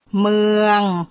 мЫанг